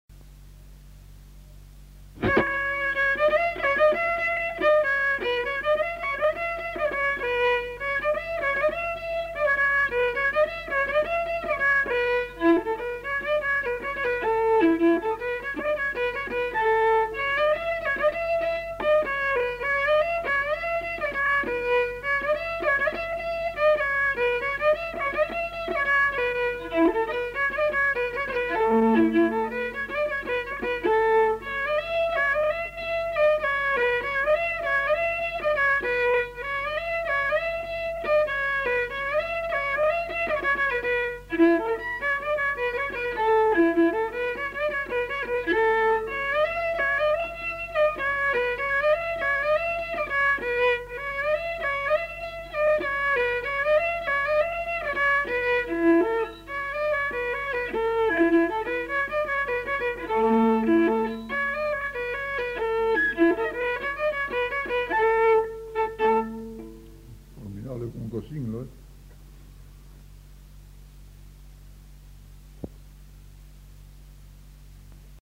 Aire culturelle : Haut-Agenais
Genre : morceau instrumental
Instrument de musique : violon
Danse : rondeau